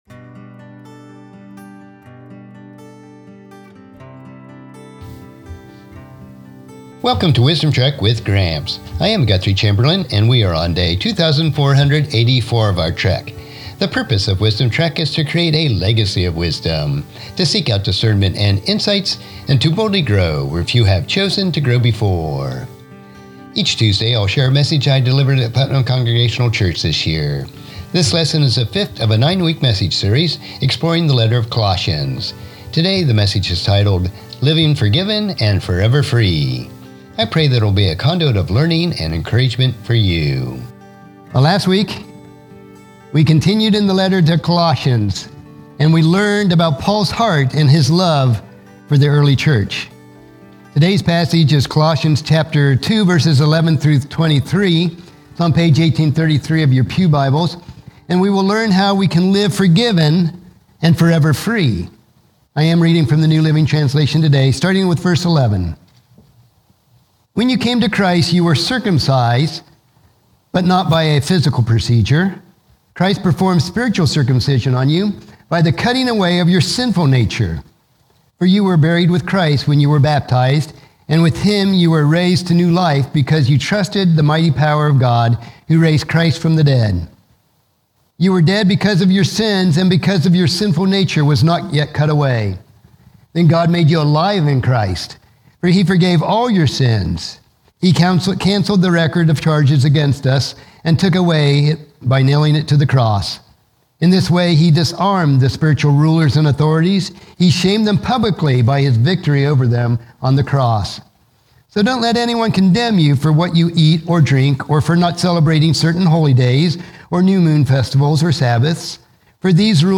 Daily Wisdom Putnam Church Message